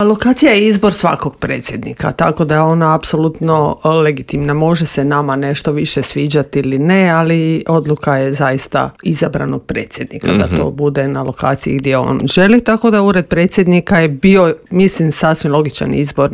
Intervjuu